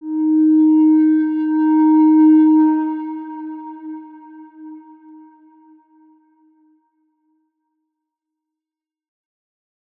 X_Windwistle-D#3-ff.wav